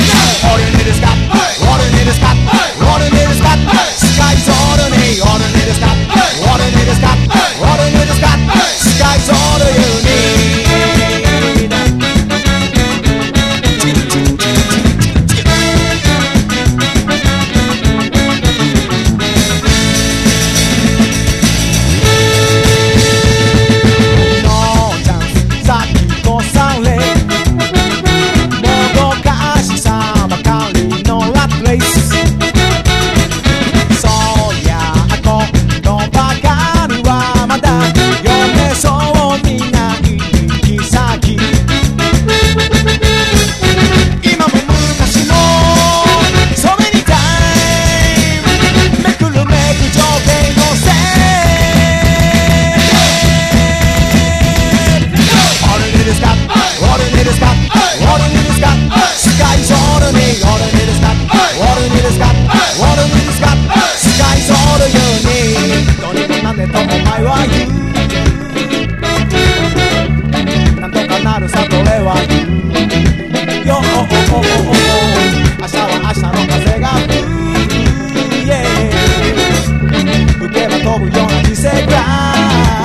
NEO SKA
スイスのオーセンティック・スカ·バンド
ジャングル・ビート風のドラムにスパイスコア感覚のホーンがスリリングに降り注ぐ